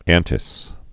(ăntĭs)